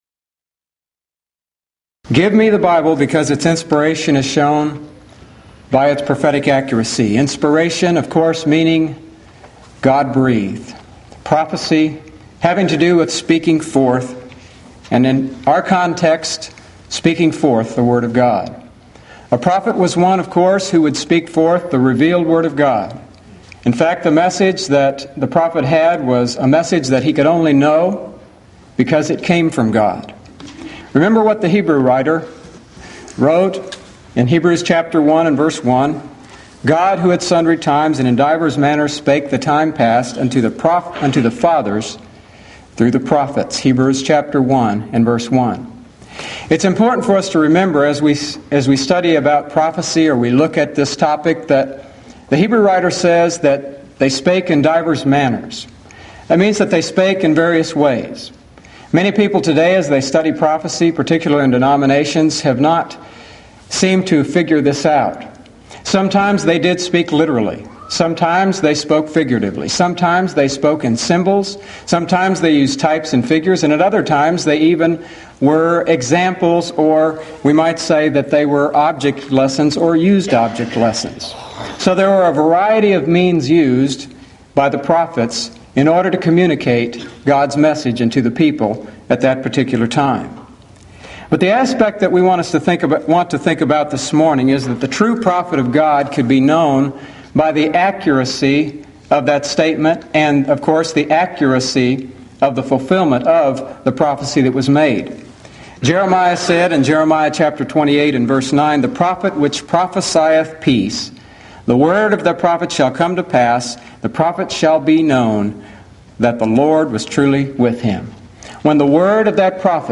Event: 1996 Mid-West Lectures
lecture